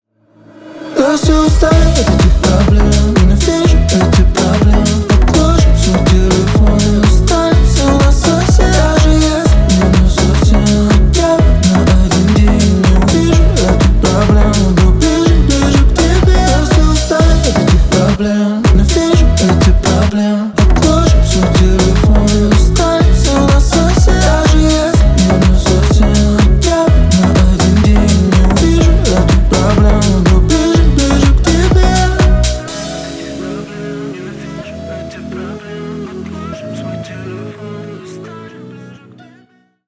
• Качество: 320 kbps, Stereo
Поп Музыка